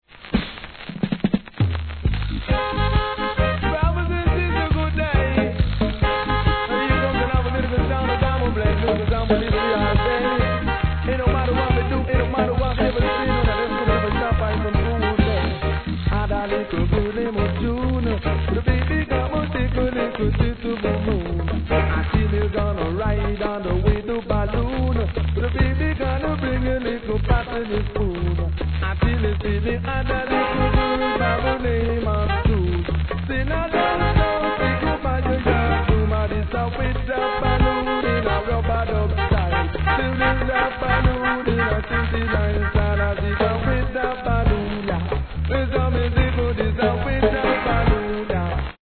REGGAE
イントロからCLASSICなSTYLEで見事に表現します。